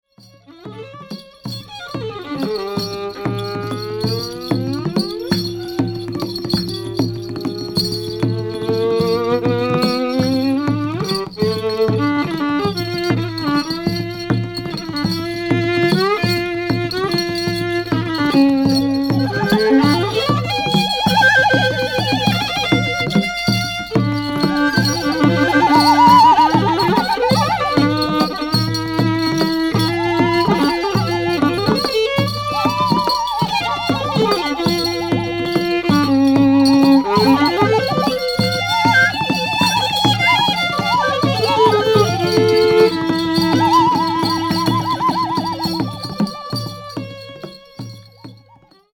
即興　霊性　地中海